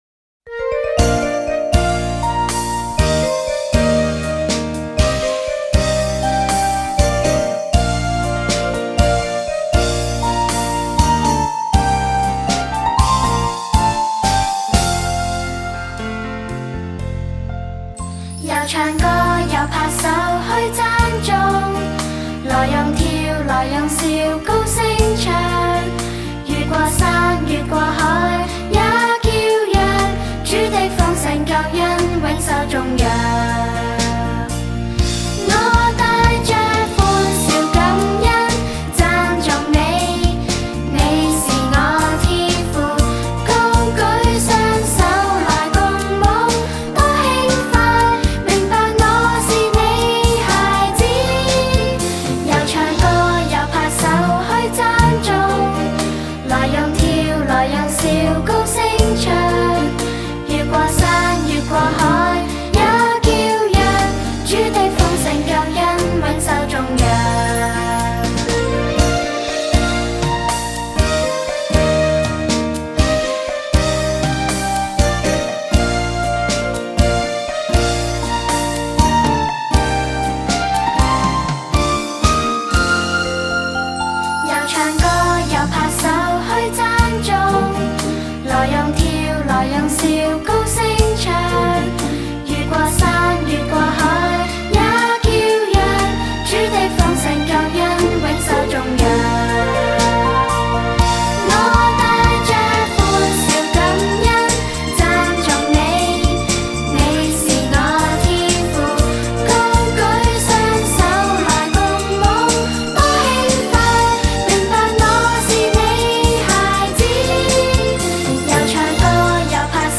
视频里有动作演示，音频里歌会自动重复三遍。